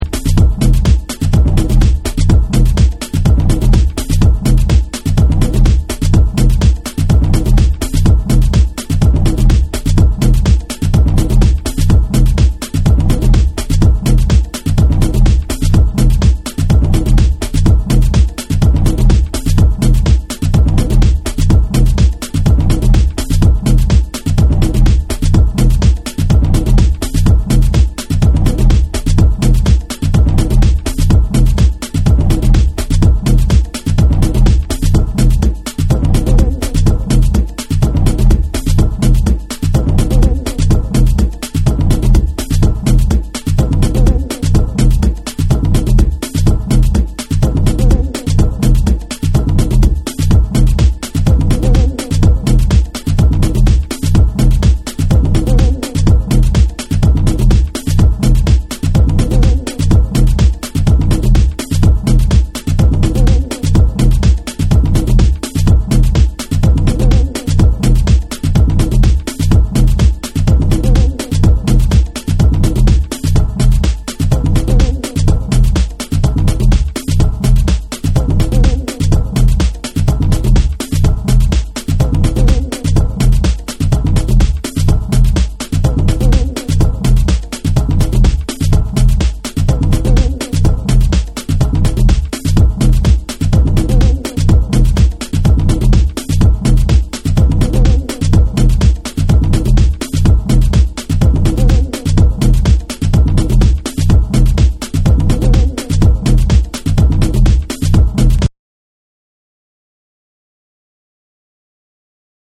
パーカッションを絡めたビートと個性的なシンセ使いで、ディープ・ハウスとミニマルの中間をいく好ナンバーを収録。
TECHNO & HOUSE